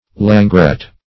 Langret \Lan"gret\, n.